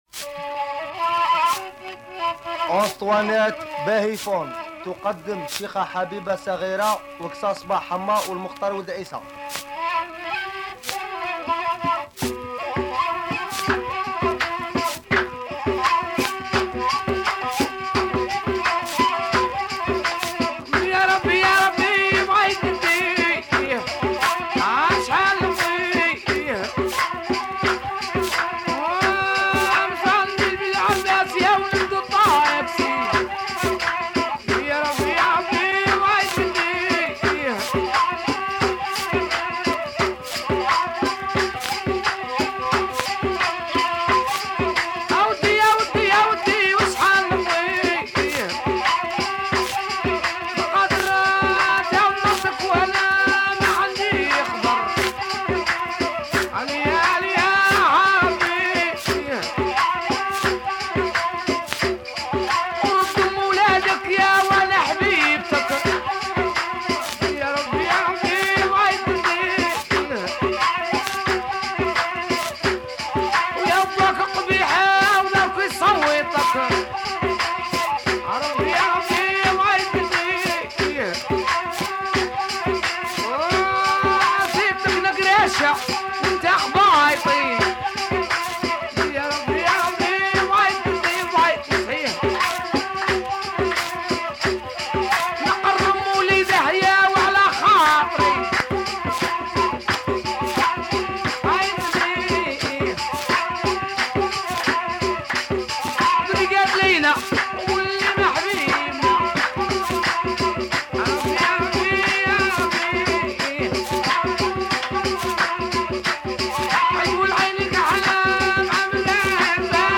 Super proto rai
Beautiful trance music, raw female chants.